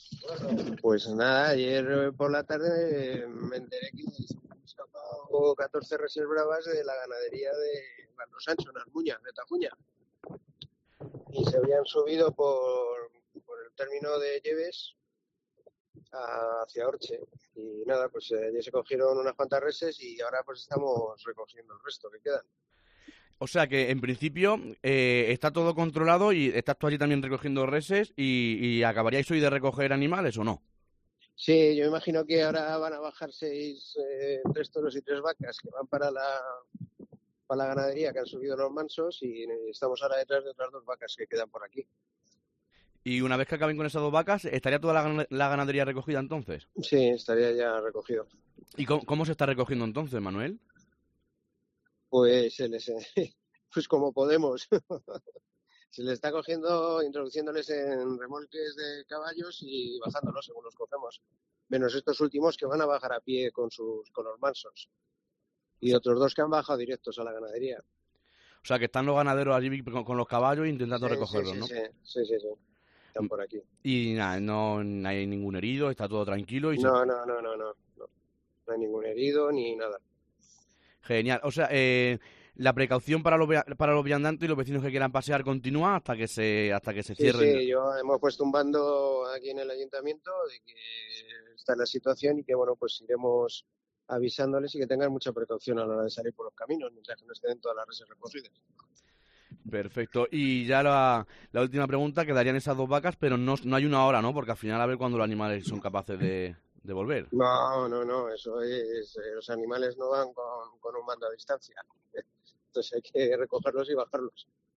El alcalde de Horche, Manuel Salvador nos contaba en COPE Castilla la Mancha que "ayer por la tarde me enteré que se habían escapado 14 reses de la ganadería, y se habrían subido por el término de Yebes hacia Horche. ya se cogieron unas cuantas reses y ahora pues estamos recogiendo el resto que queda".
Justo en la llamada telefónica pillábamos a Manuel ayudando en las tareas de recogida "ahora van a bajar seis, tres toros y tres vacas que van para la ganadería, han subido los mansos y estamos ahora detrás de otras dos vacas que quedan por aquí".